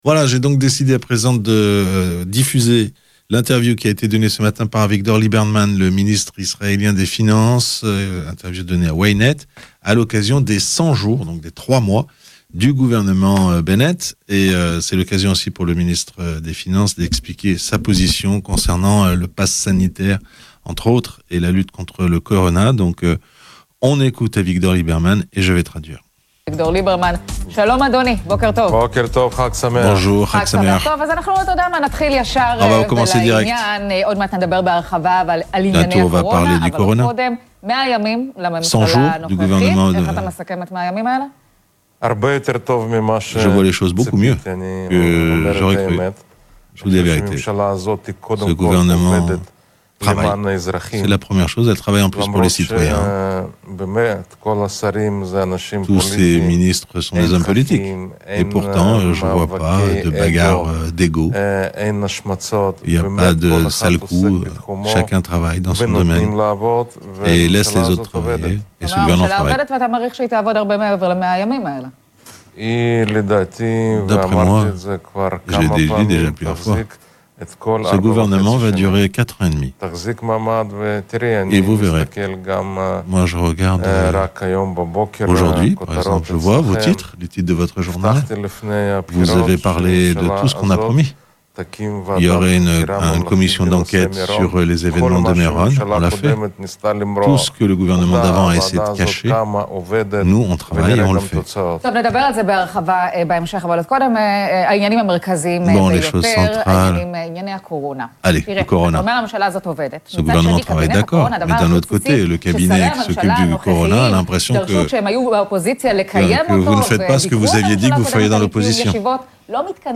Le ministre israélien des finances était l'invité de Ynet.